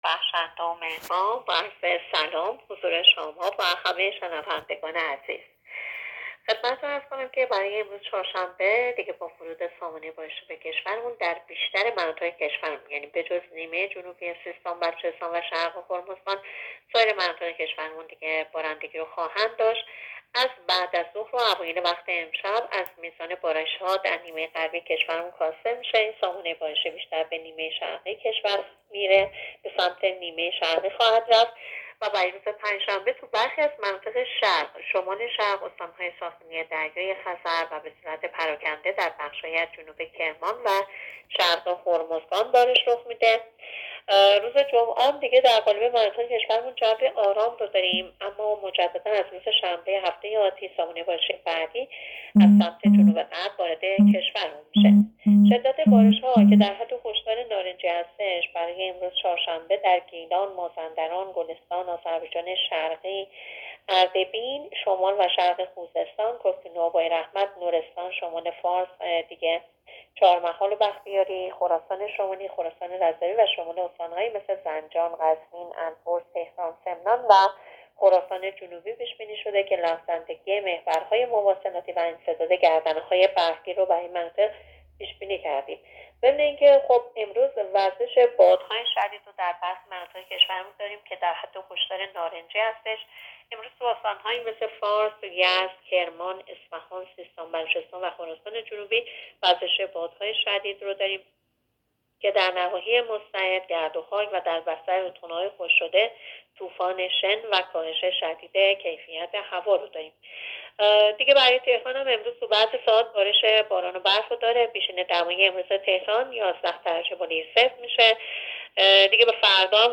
گزارش رادیو اینترنتی از آخرین وضعیت آب و هوای پانزدهم بهمن؛